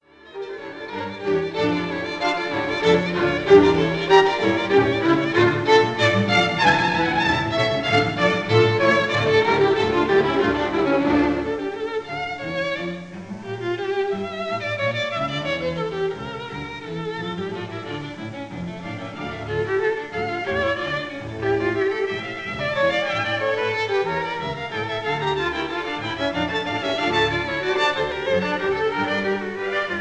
conductor
historic 1932 recording